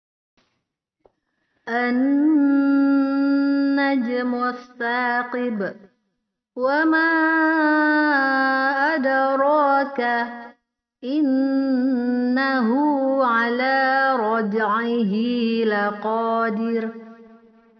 Qalqalah Ringan yaitu apabila salah satu dari huruf qalqalah sukun berada di tengah kata. Cara bacanya yaitu langsung setelah dipantulkan disambung kehuruf setelahnya.